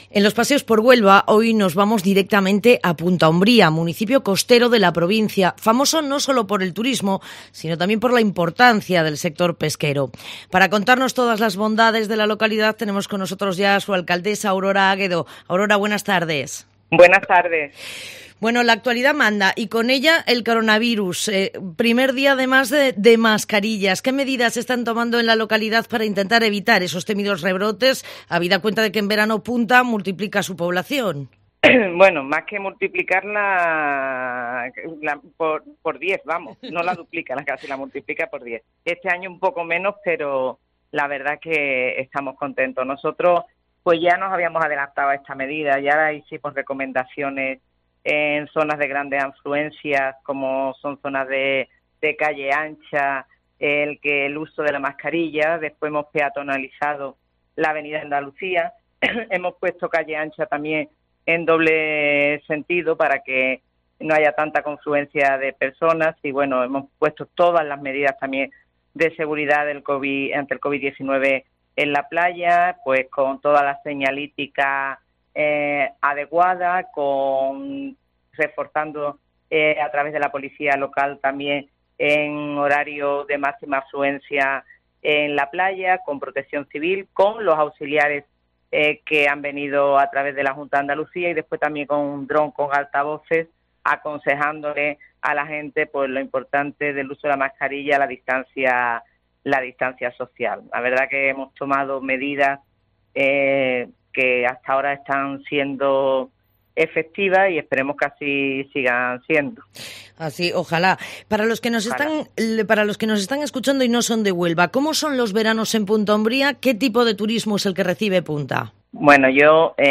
La alcaldesa de Punta Umbría destaca en COPE las bondades de la localidad